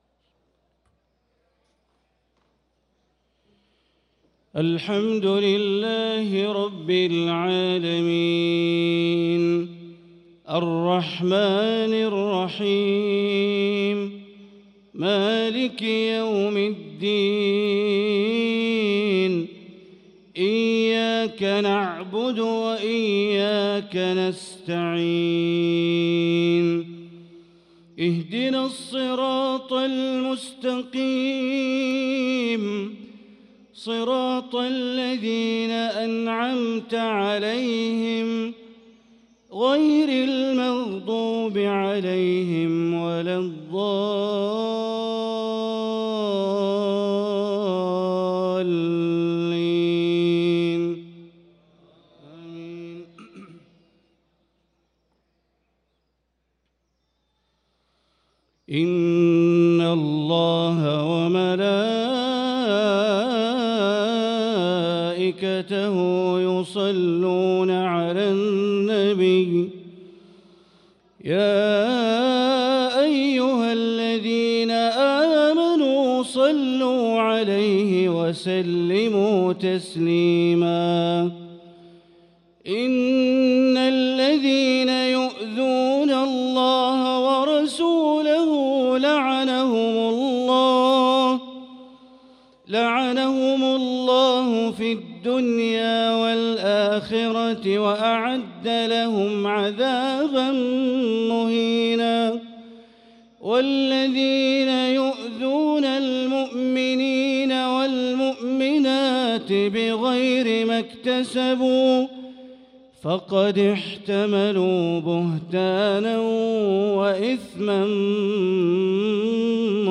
صلاة العشاء للقارئ بندر بليلة 27 رجب 1445 هـ